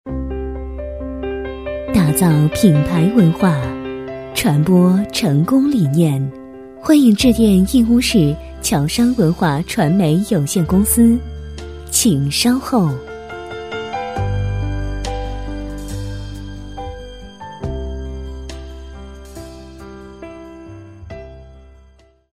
女声配音
彩铃女国47B